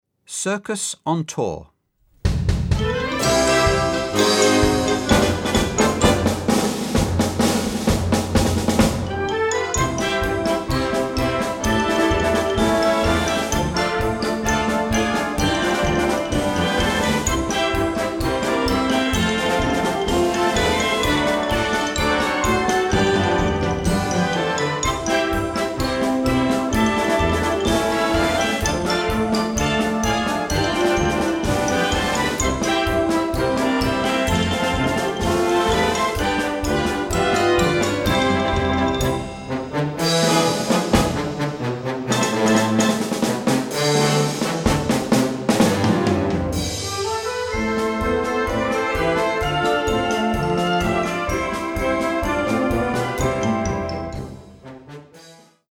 Gattung: Zirkusmusik
Besetzung: Blasorchester